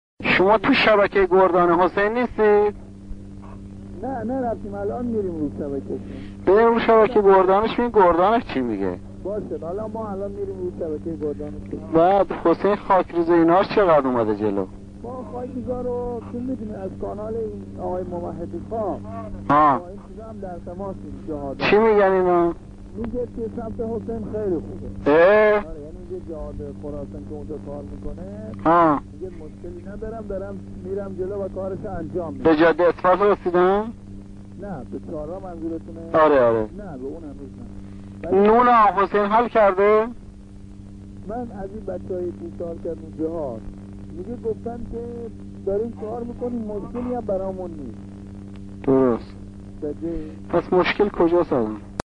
مکالمه درباره وضعیت پیشروی خاکریزهای گردان امام حسین، خاکریزها جلو آمده و وضعیت خوب است،ولی هنوز اقدام به پیشروی در جاده ها نشده ، می‌گویند مشکلی برای پیشروی